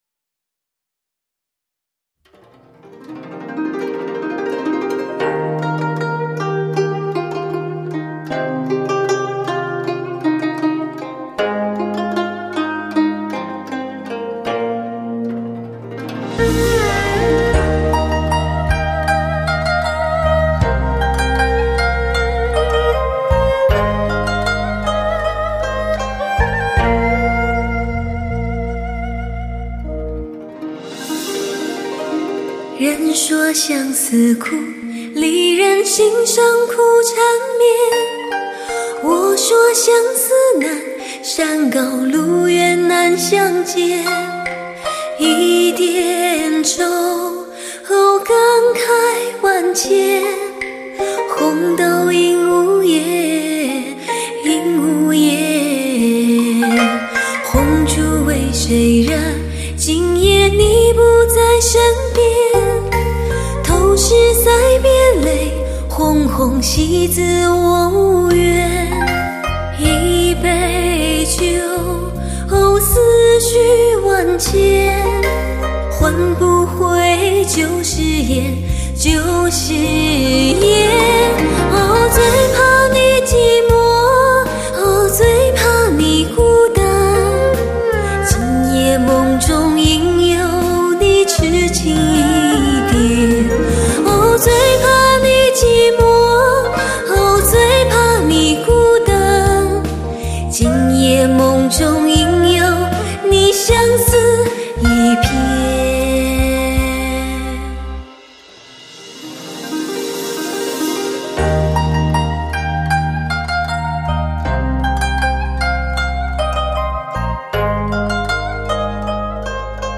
唯美恬静，完美发烧声音质感女声；
高贵天籁，极至典范录音品质天碟！